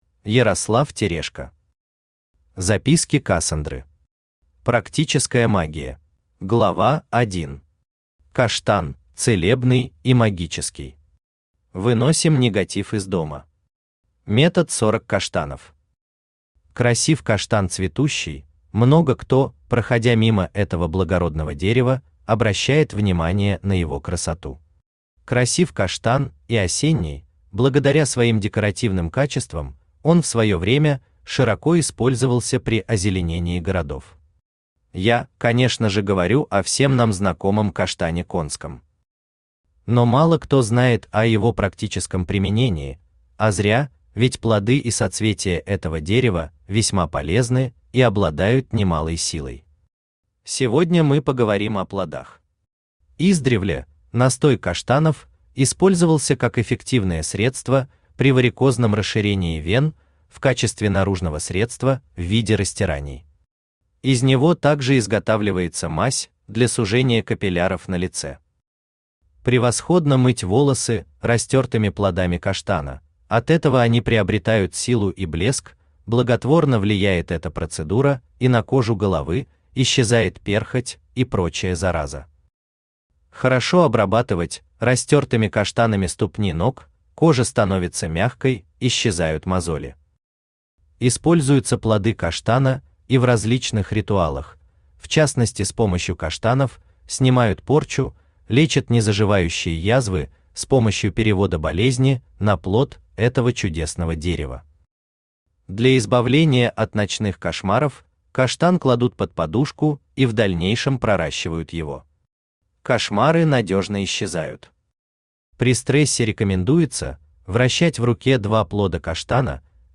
Практическая Магия Автор Ярослав Леонидович Терешко Читает аудиокнигу Авточтец ЛитРес.